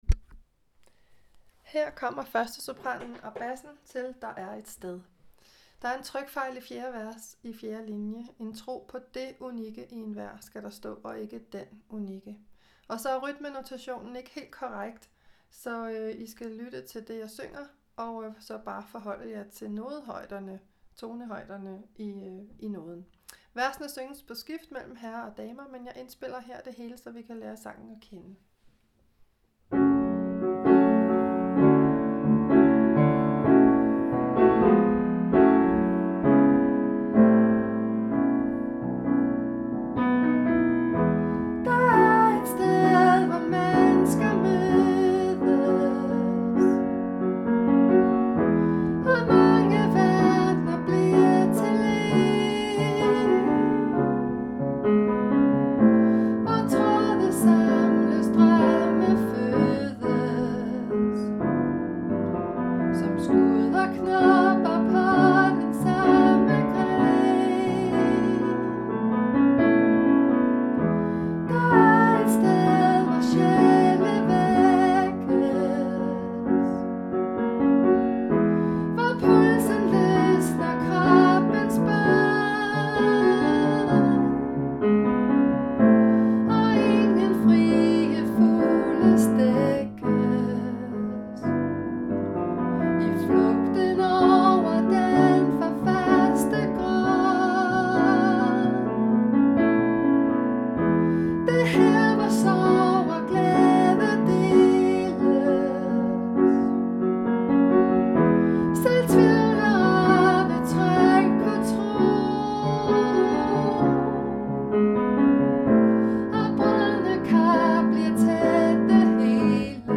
Lydfiler Sopran
Der-er-et-sted-1.-sopranbas.mp3